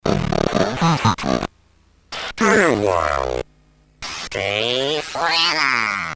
A simple digital voice changer unit with pitch transpose and robot-voice speech mode.
ac ROBOT MODEthis special function freezes the incoming voice to a fixed tonality.
demo AUDIO DEMO